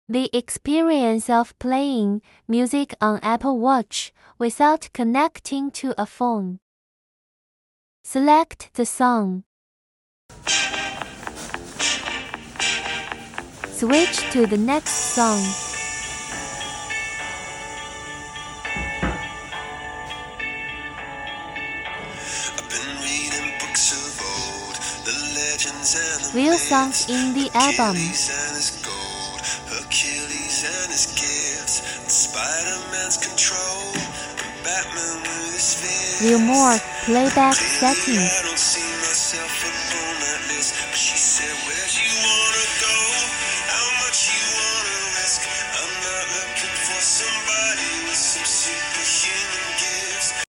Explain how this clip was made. Interesting Experience: playing music through Apple Watch speaker.